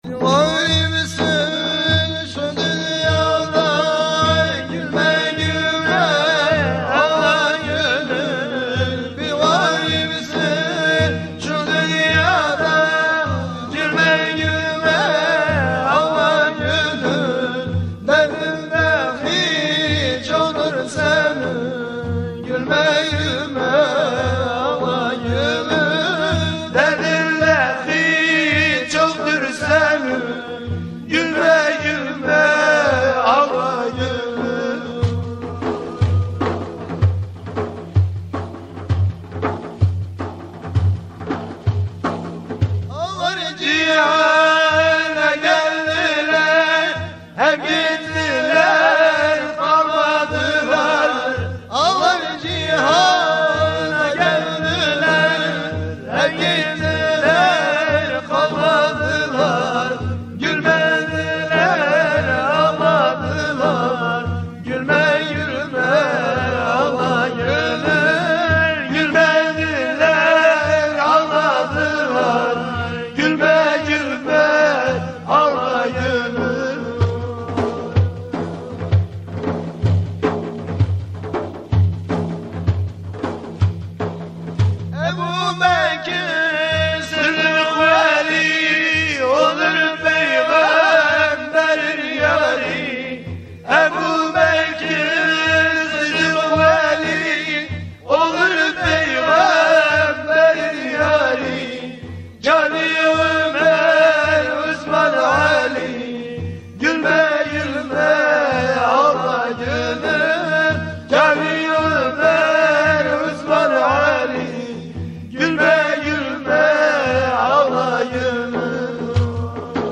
Etiketler: şanlıurfa, Tasavvuf